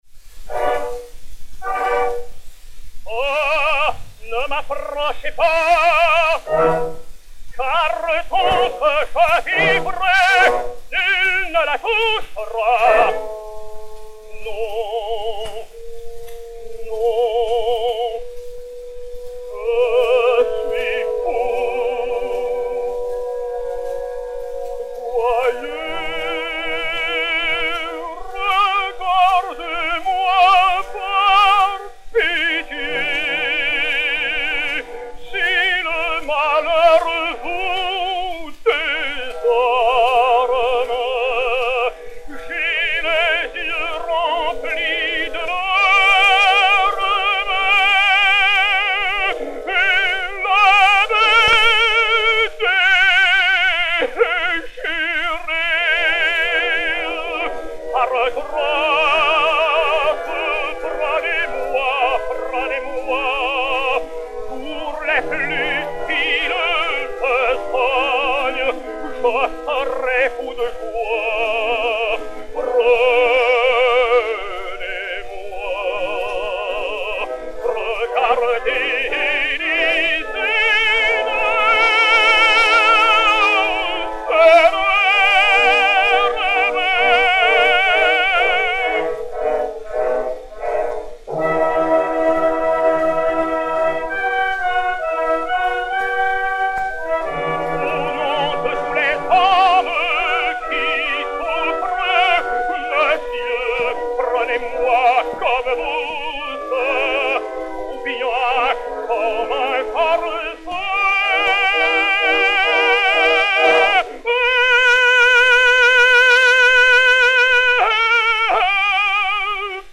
Léon Campagnola (Des Grieux) et Orchestre
Disque Pour Gramophone 4-32303, mat. 17203u, enr. à Paris le 13 mai 1912